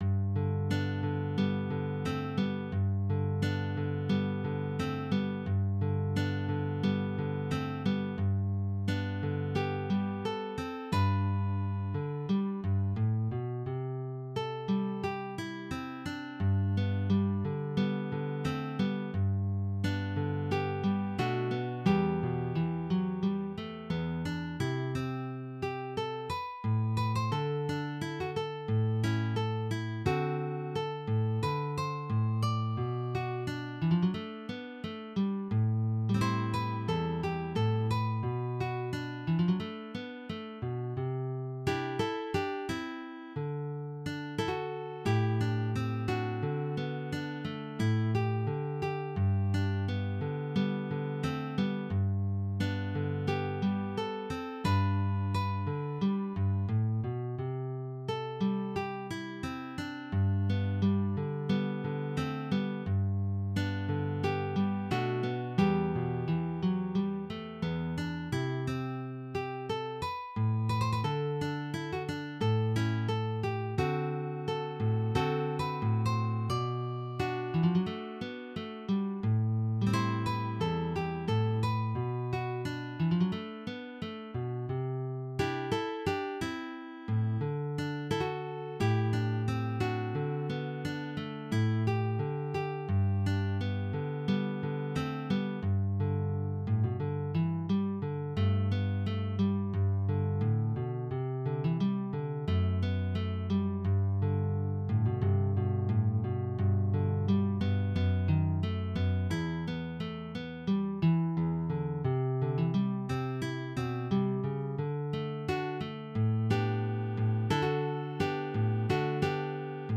DIGITAL SHEET MUSIC - CLASSIC GUITAR SOLO
Traditional Ballad